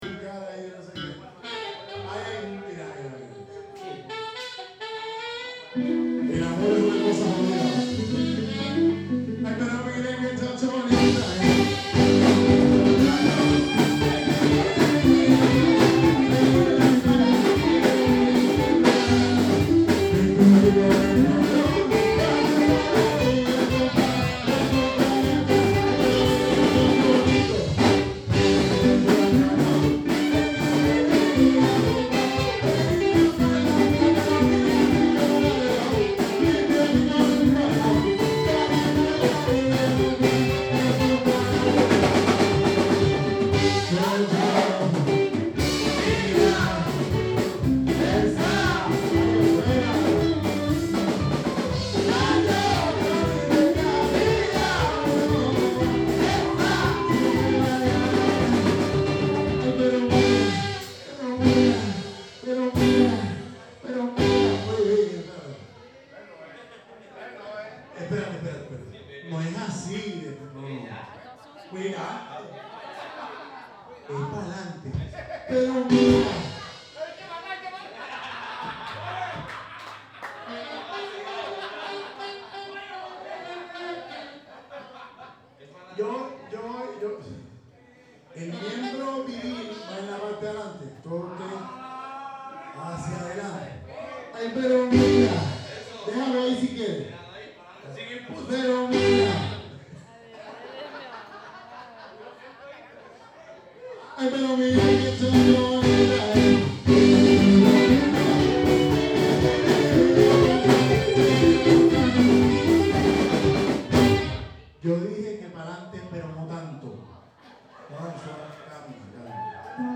Prête moi tes yeux : le concert de cumbia
La cumbia, c'est le style du musique traditionnel colombien qui m'a attirée sur la côte caribéenne, voir même en Colombie.
Les musiciens ressemblent plus à une équipe de rugby qui fête un bon match plutôt qu'à un groupe de musique traditionnelle.